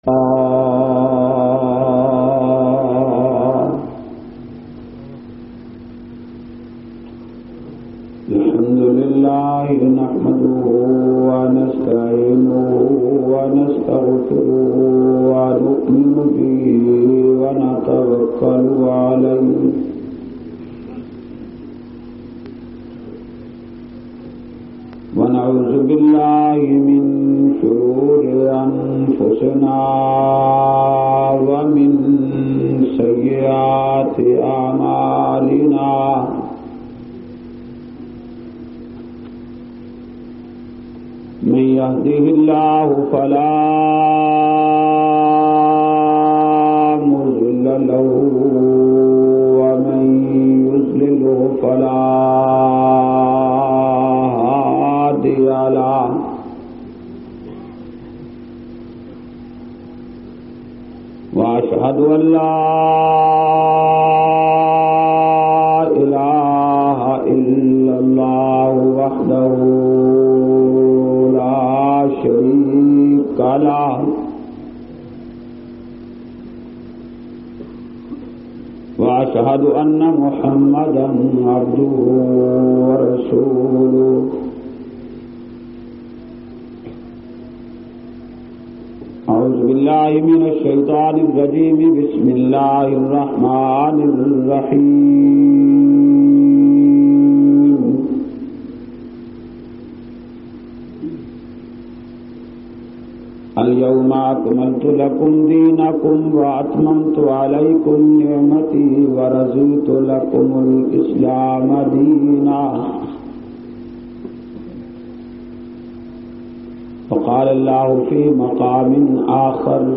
323- Azmat e Quran Confrance-Ishaat Ul Quran,Hazro.mp3